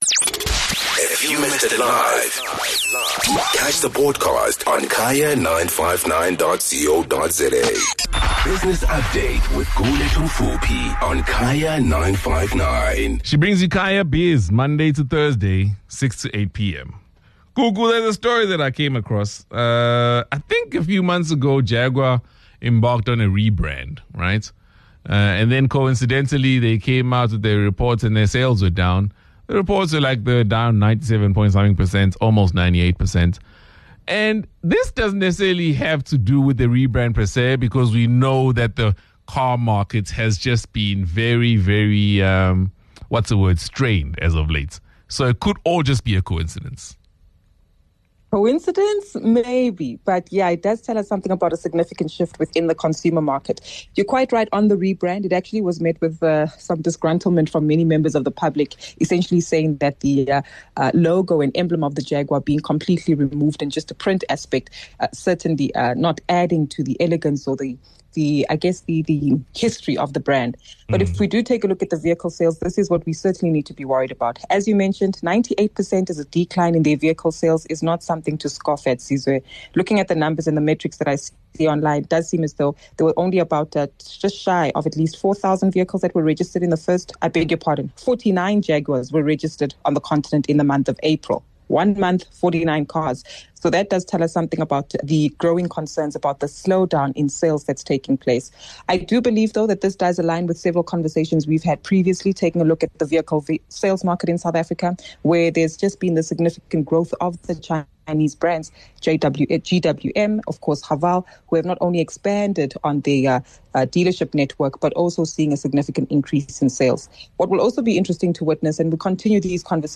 2 Jul Business Update: Jaguar sales plummets & Concerns in online gambling surge.